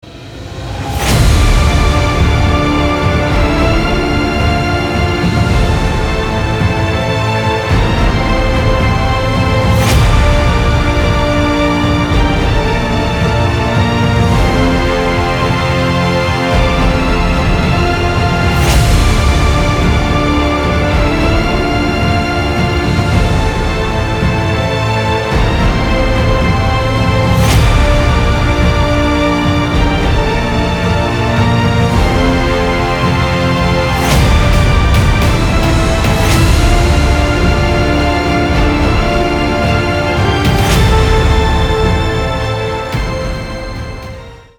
• Качество: 320, Stereo
громкие
без слов
инструментальные
оркестр
эпичные
Эпическая и оркестровая музыка